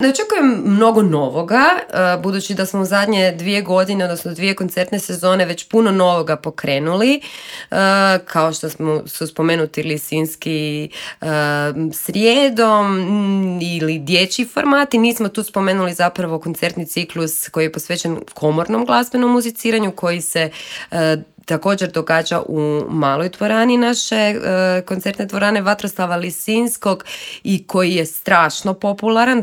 Dolaskom jeseni stiže nova sezona u Koncertoj dvorani Vatroslav Lisinski. O nadolazećim koncertima i novim programima u studiju Media Servisa